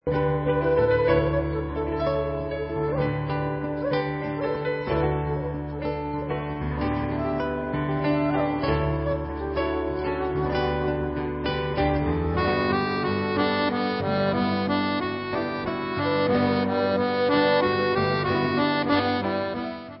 LIVE IN BRUSSELS